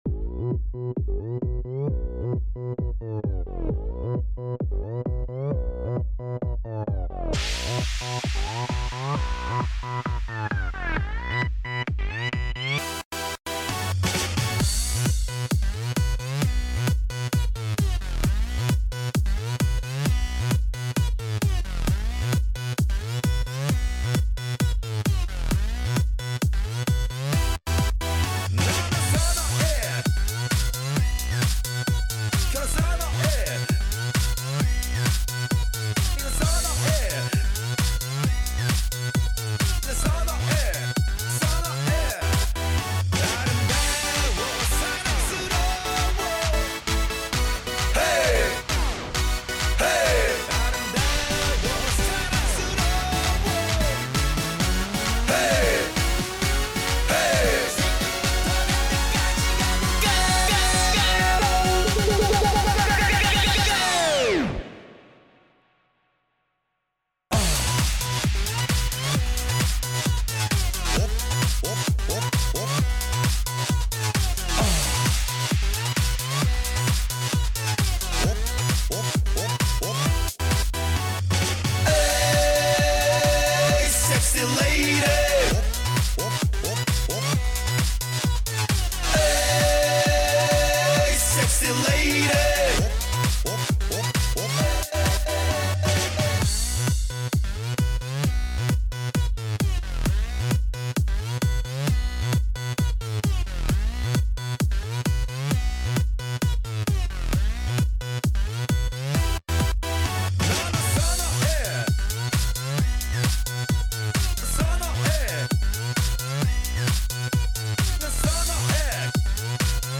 минусовка версия 100449